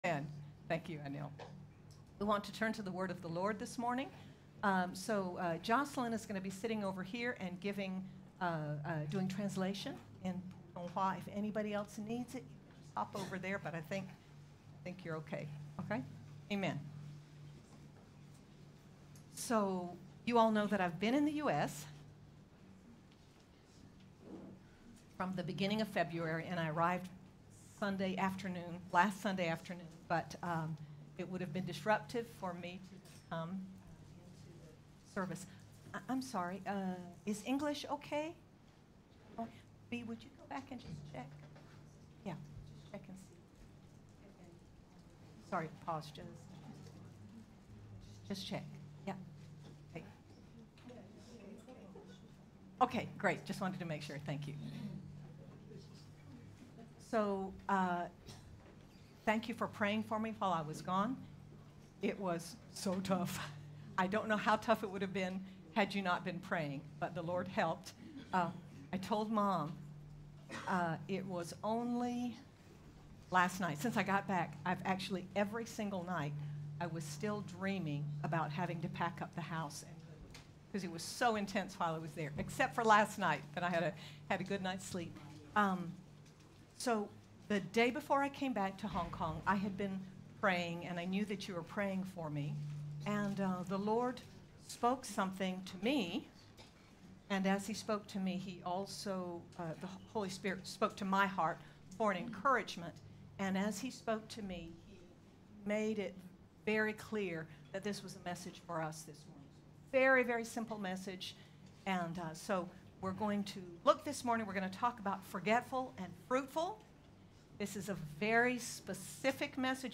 A look at the life of Joseph shows how God works to bring about His good for us and through us in all our troubles. Sermon by